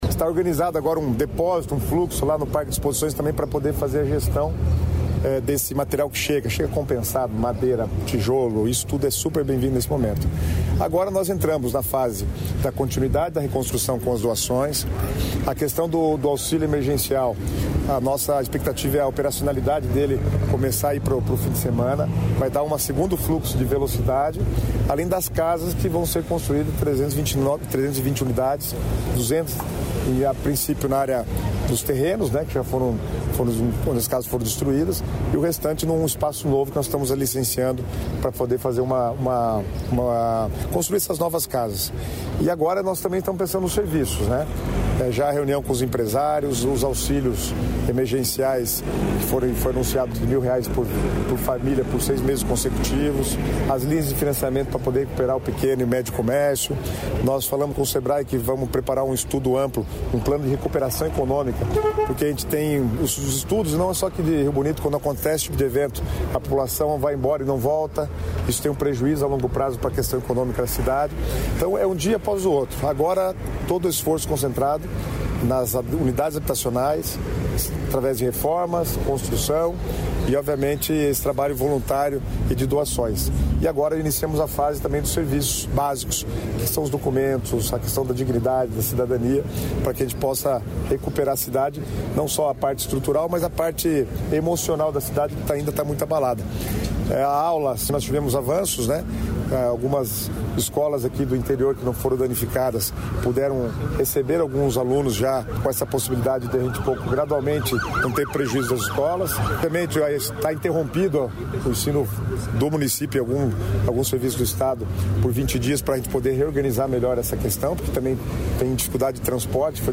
Sonora do secretário de Estado das Cidades, Guto Silva, sobre a vistoria em Rio Bonito do Iguaçu